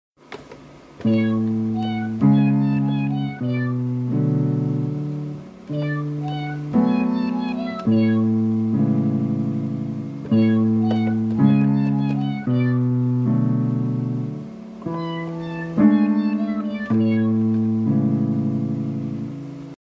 Back at my apartment so no more vintage piano. also lost my sustain pedal for my Yamaha